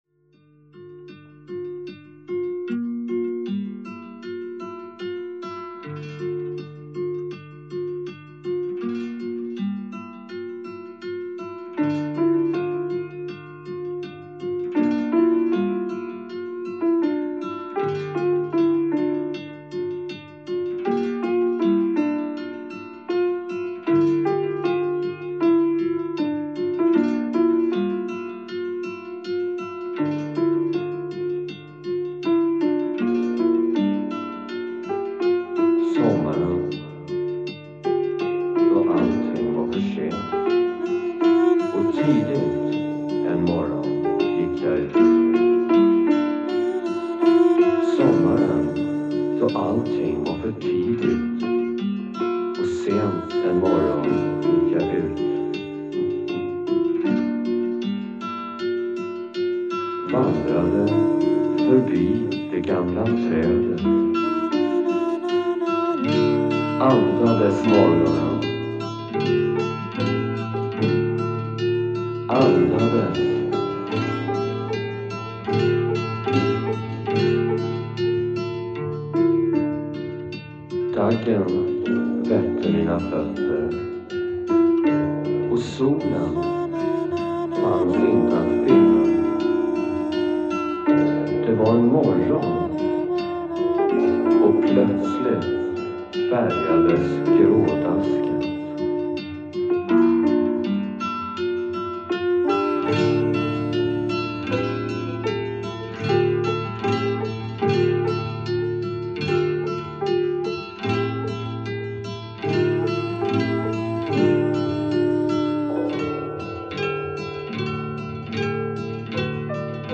Voice, Piano
Guitar, Drums